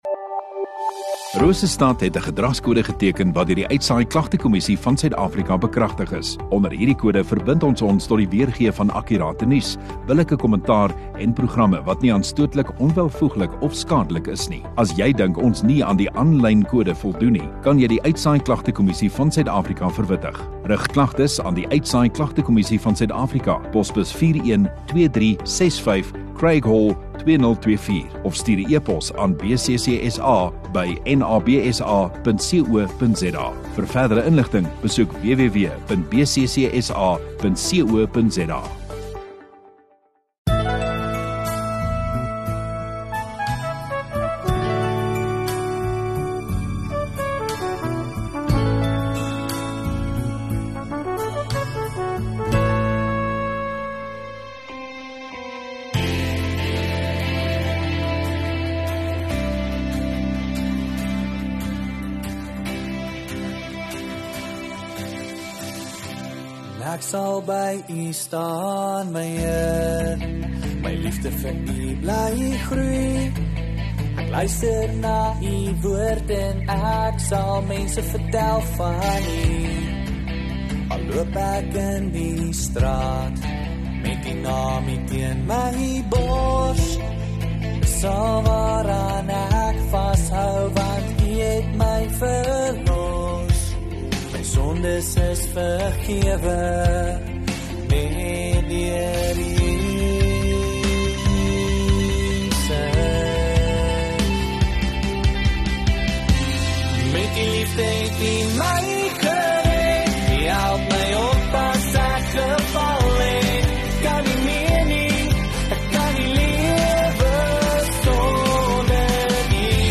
1 Mar Sondagaand Erediens